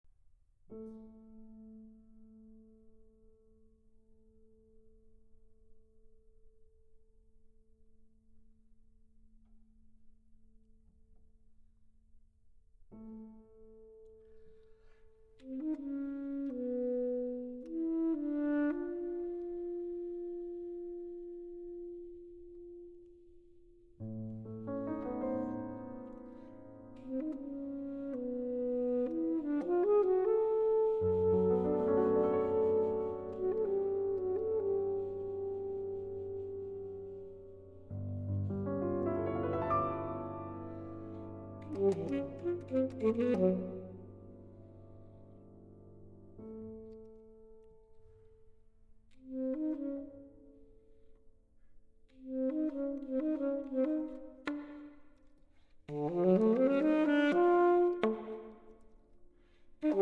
Sonata for Alto Saxophone and Piano